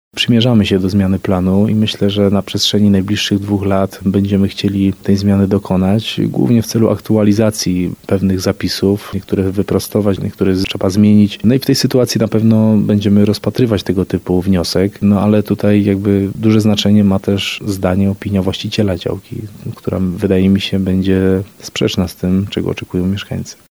Wójt Jacek Kaliński pytany o sprawę mówi, że gmina przymierza się do zmiany planu. Zmiana ta powinna się dokonać w perspektywie dwóch lat.